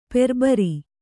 ♪ perbari